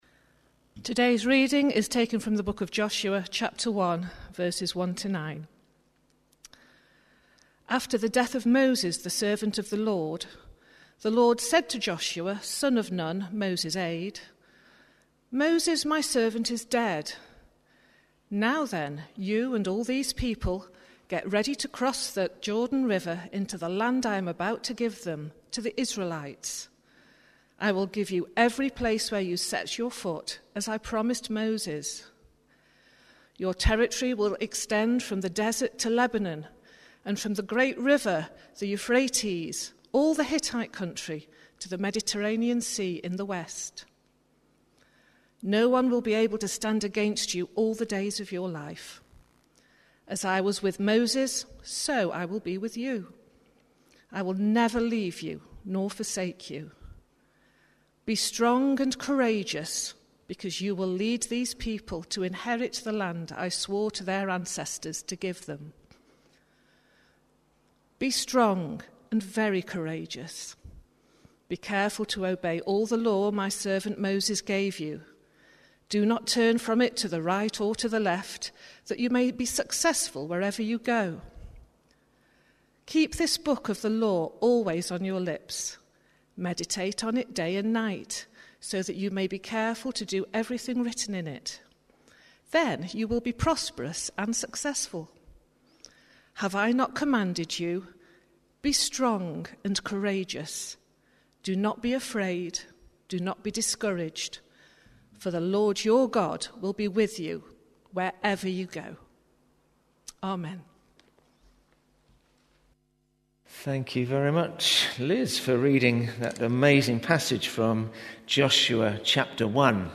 Watch Listen play pause mute unmute Download MP3 Thanks for joining us this morning as we come together both online and in-person to worship! We're starting a new series today looking at some of the lessons we can learn from the life of Joshua.